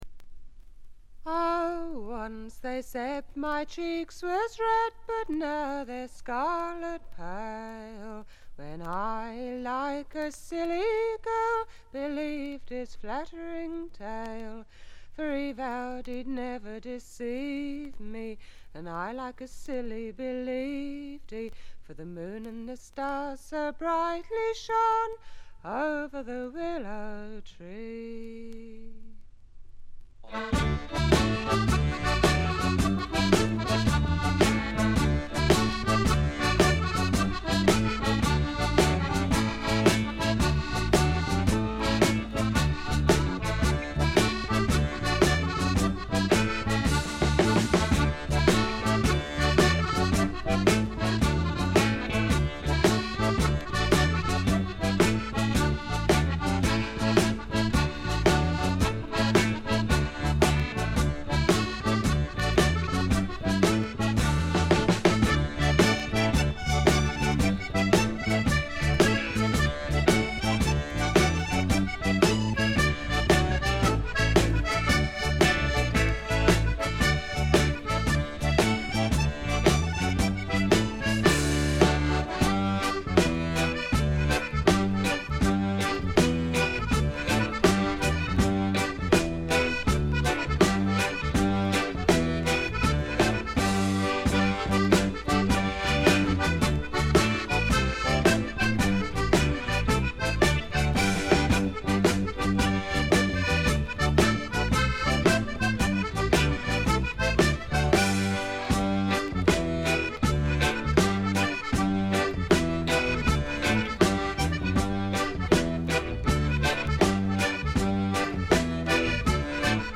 静音部の軽微なチリプチ程度。
エレクトリック・トラッド基本中の基本！
試聴曲は現品からの取り込み音源です。